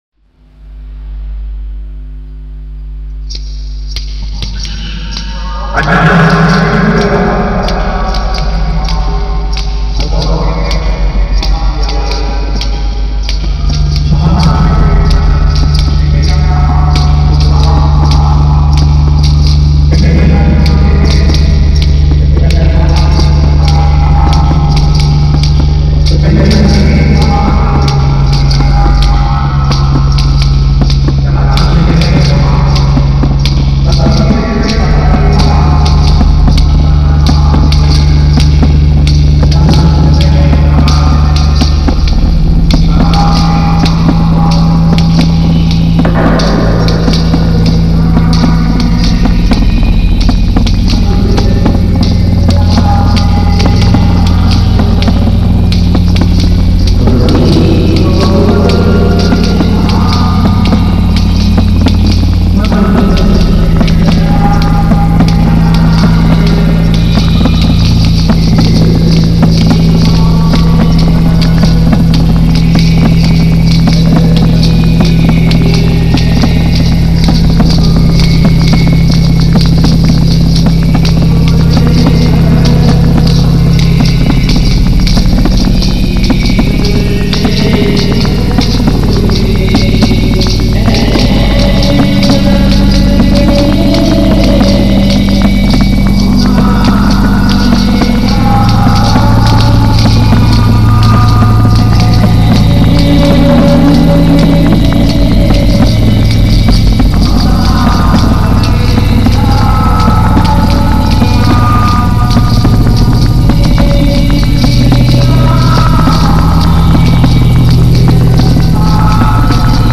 noise, experimental, drone, dark ambient